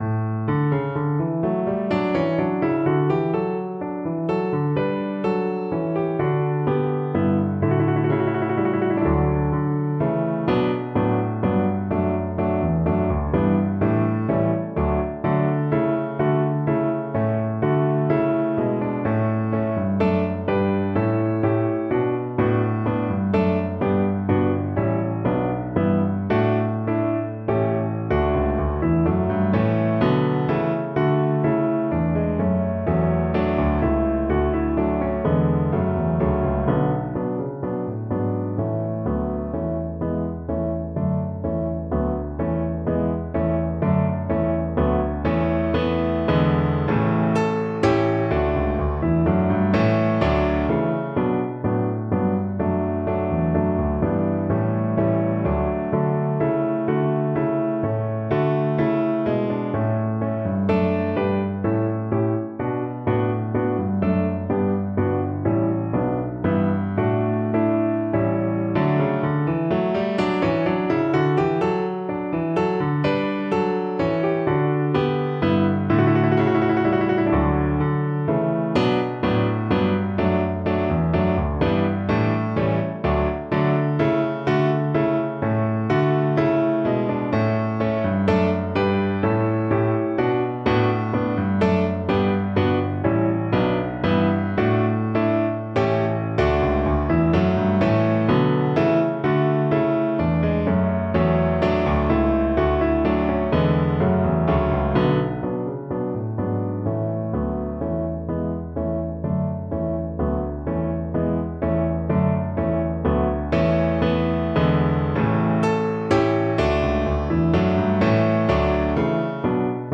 Classical Scarlatti, Alessandro Già il sole dal Gange (O'er Ganges now launches) Canzonetta Cello version
Free Sheet music for Cello
Cello
D major (Sounding Pitch) (View more D major Music for Cello )
Allegro giusto =126 (View more music marked Allegro)
3/4 (View more 3/4 Music)
A3-B4
Classical (View more Classical Cello Music)